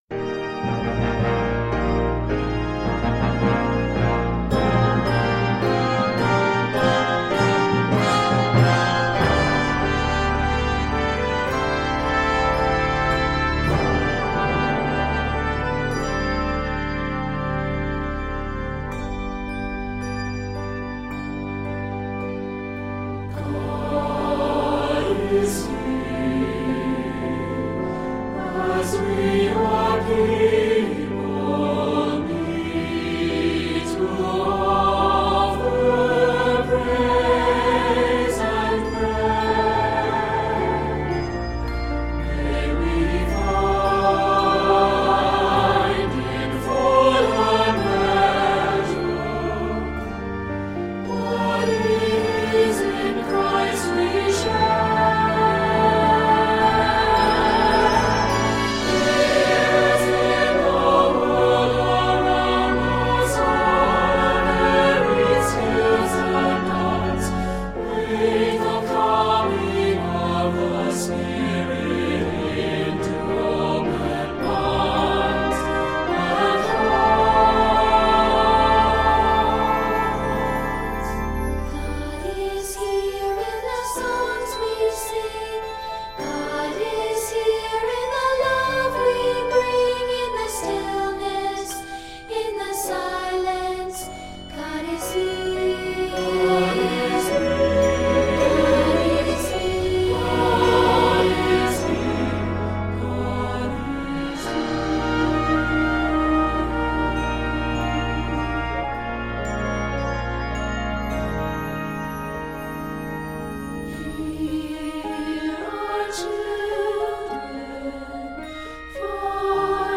majestic, new musical treatment